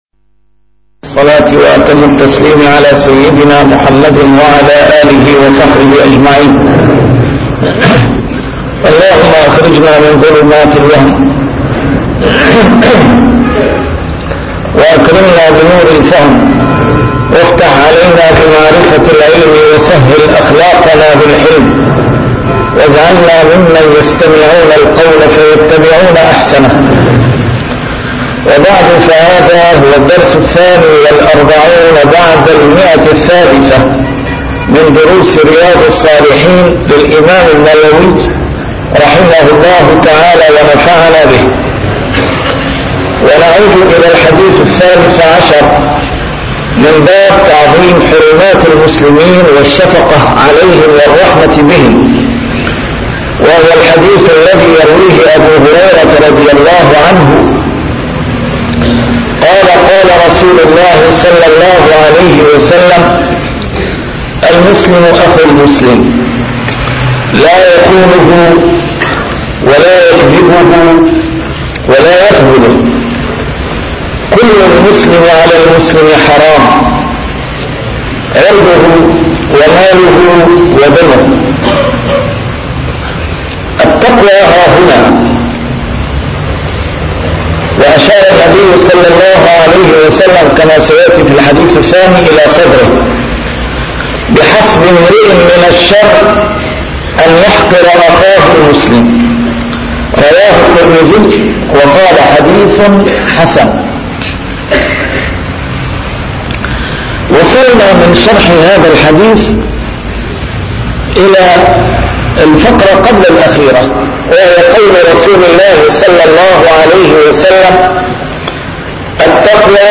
شرح كتاب رياض الصالحين - A MARTYR SCHOLAR: IMAM MUHAMMAD SAEED RAMADAN AL-BOUTI - الدروس العلمية - علوم الحديث الشريف - 342- شرح رياض الصالحين: تعظيم حرمات المسلمين